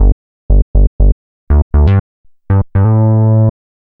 Session 08 - Bass 05.wav